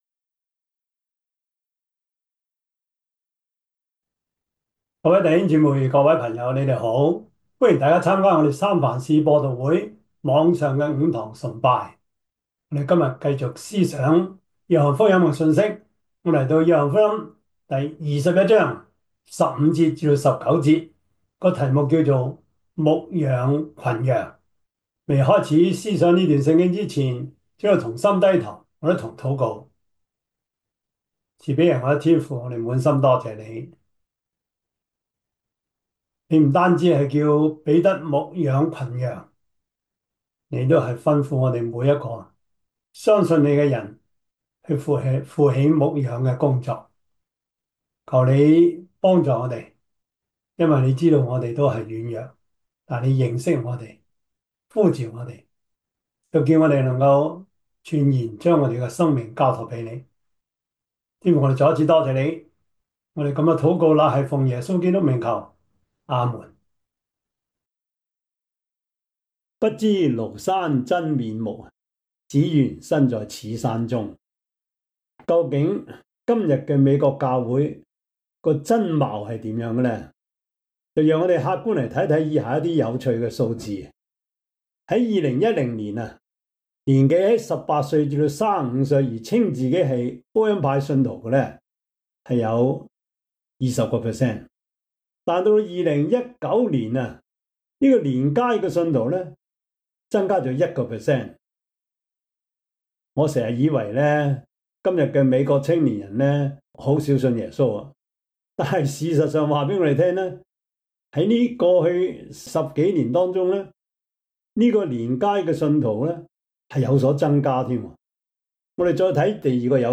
Preacher
Service Type: 主日崇拜